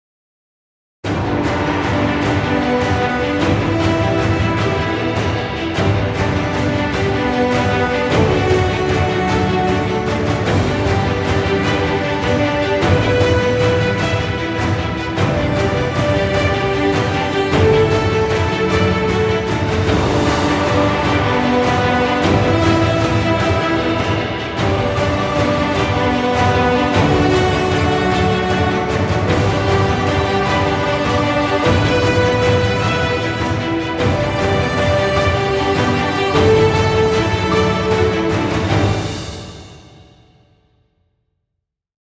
cinematic orchestral music.